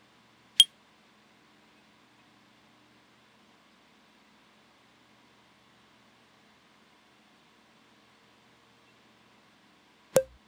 Getting rid of ear piercing clicks
If the pings/blips are louder than everything else, the popmute plugin can attenuate all of them in one pass.